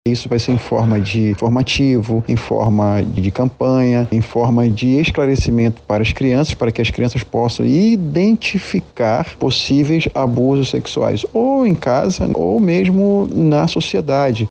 O autor do projeto intitulado “Escola que Cuida”, explica como seria feita a abordagem com os estudantes.